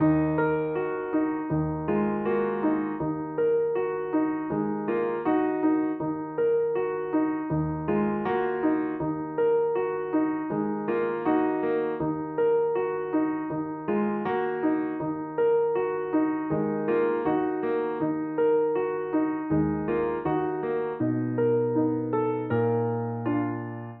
Dark Keys 2 BPM 80.wav